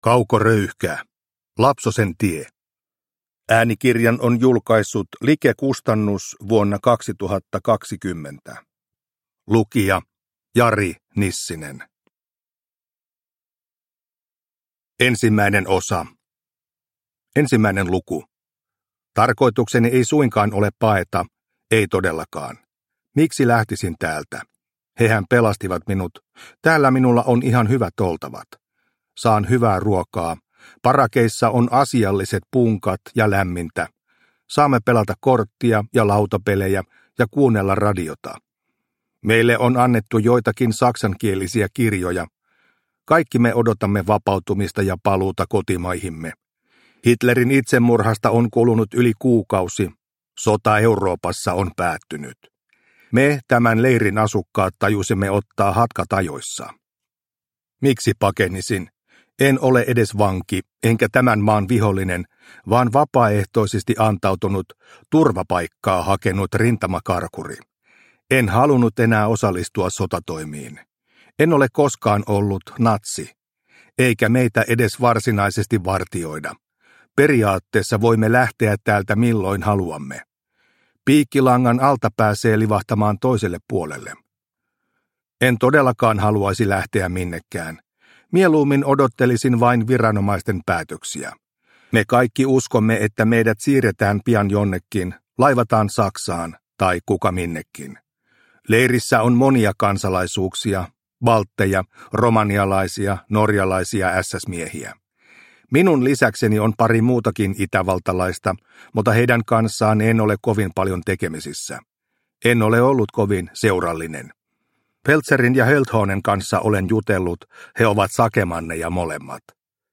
Lapsosen tie – Ljudbok – Laddas ner